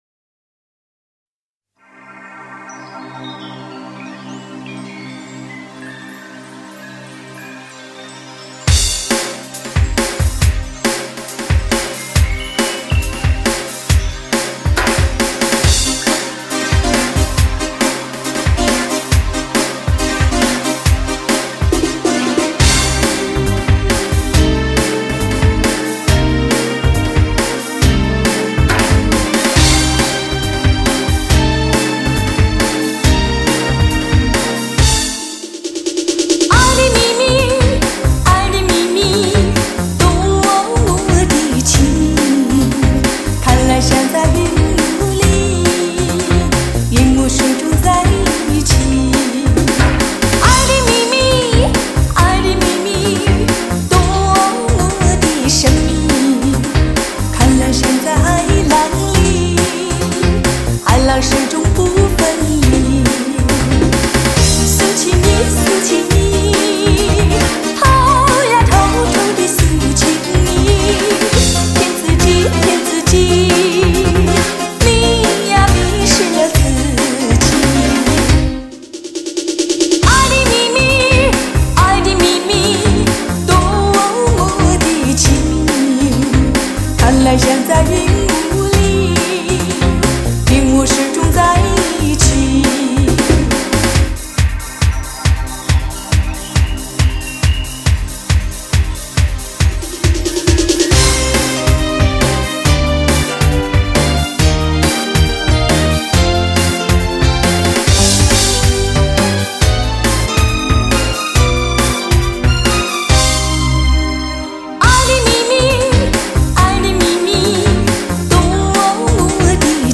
首度以高保真CD正式出版 原始母带经高新科技原音处理
既保留了黑胶唱片的暖和柔美 也展现了数码唱片的精确清晰
让那甜蜜柔美的声音再度飘进你的心窝 萦牵你的心弦